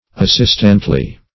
assistantly - definition of assistantly - synonyms, pronunciation, spelling from Free Dictionary Search Result for " assistantly" : The Collaborative International Dictionary of English v.0.48: Assistantly \As*sist"ant*ly\, adv.